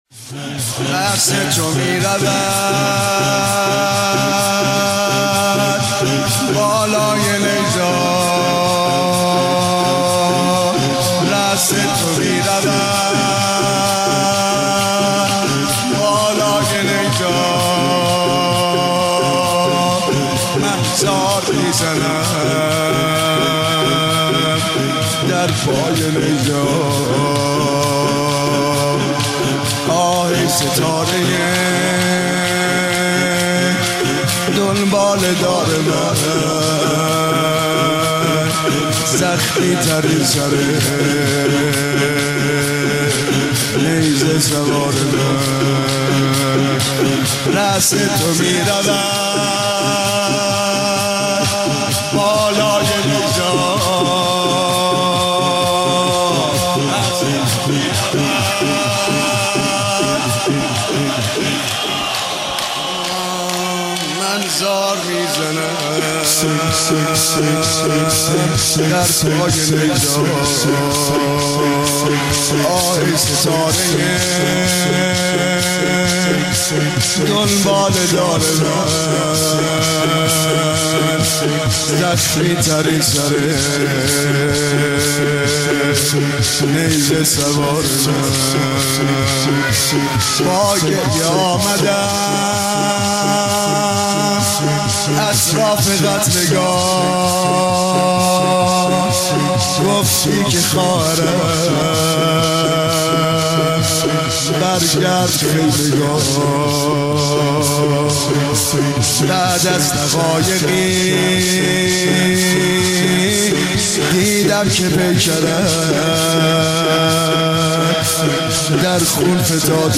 پانزدهمین اجتماع مدافعان حرم در مهدیه تهران